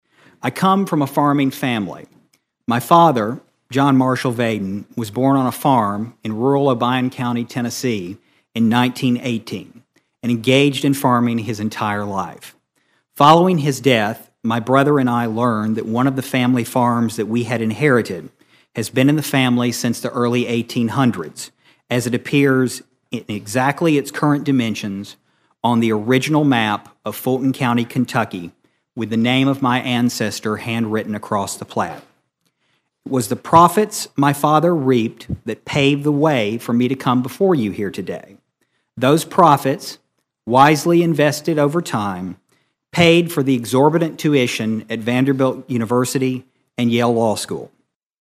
Obion County’s Stephen Vaden answered questions from lawmakers during his confirmation hearing in Washington on Tuesday.
During his appearance with the U.S. Senate Committee on Agriculture, Nutrition and Forestry, Vaden introduced himself to the committee.(AUDIO)